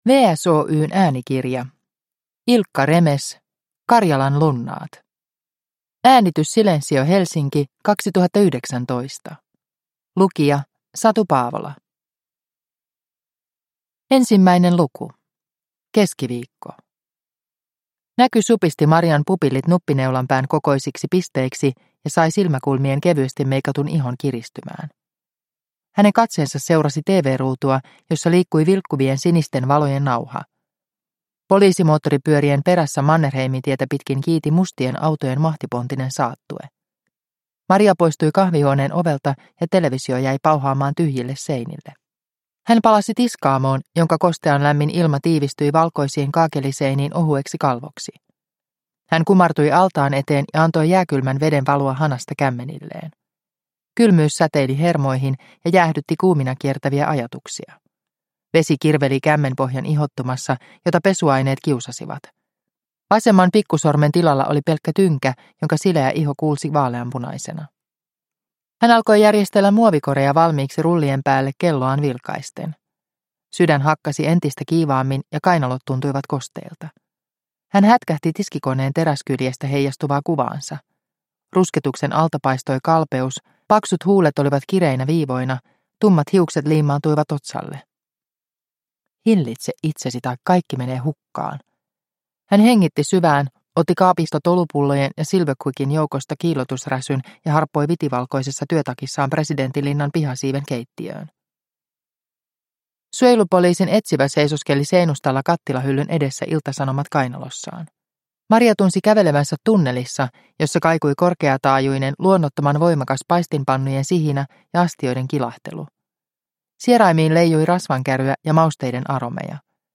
Karjalan lunnaat – Ljudbok – Laddas ner